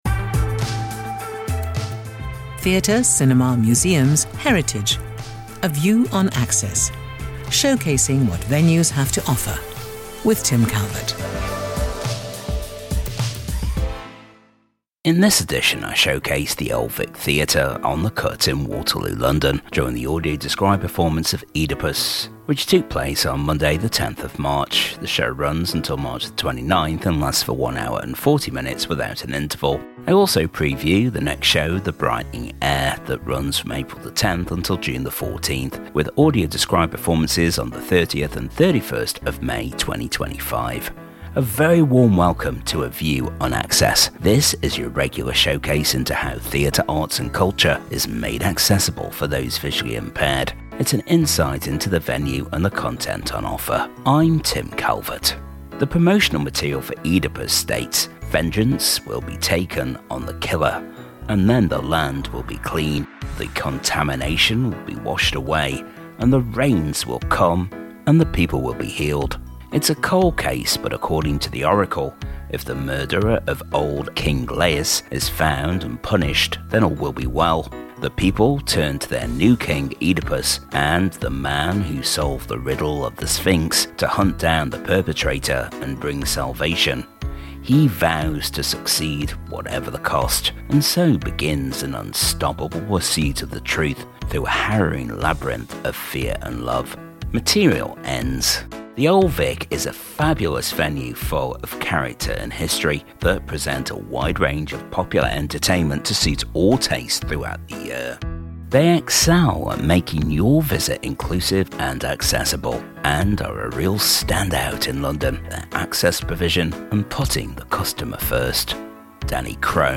In this edition we showcase the old Vic theatre on the cut in Waterloo London during the audio described performance of Oedipus during the audio described performance and touch tour that took place on Monday the 10th of March.